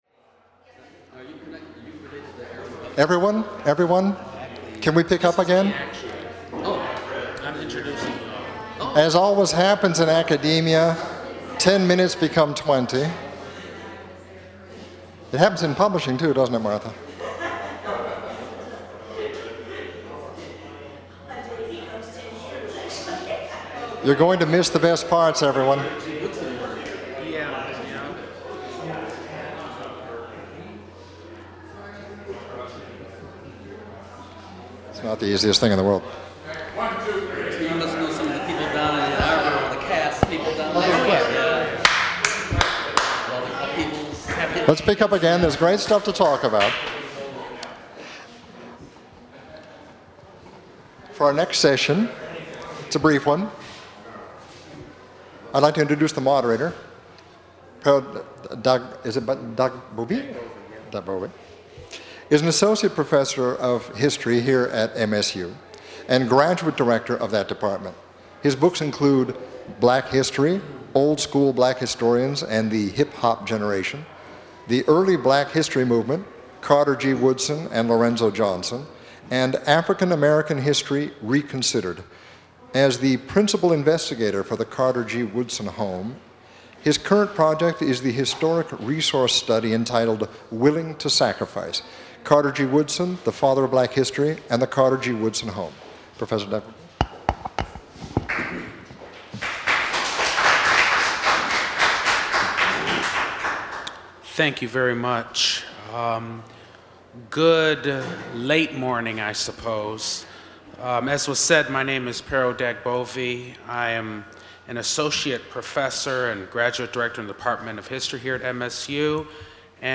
A question and answer segment concludes the session.
Criticism and interpretation Drama--Social aspects Material Type Sound recordings Language English Extent 01:30:52 Venue Note Recorded at Michigan State University Museum, Nov. 12, 2010, by the Vincent Voice Library.